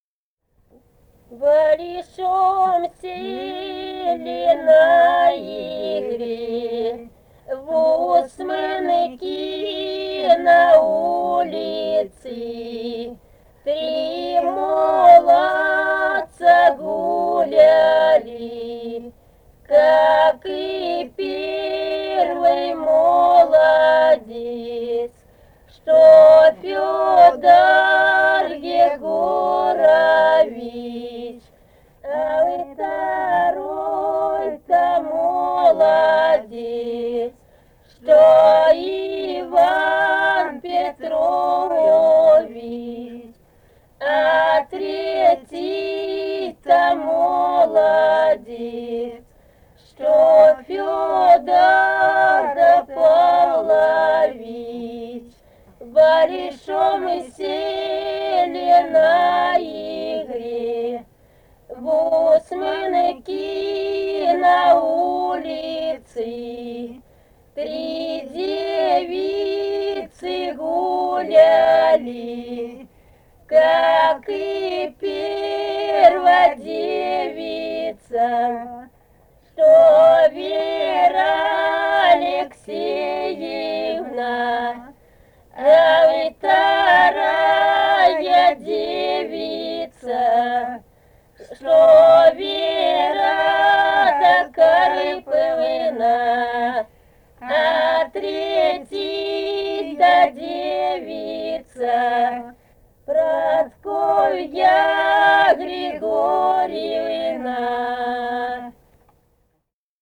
Самарская область, с. Усманка Борского района, 1972 г. И1316-13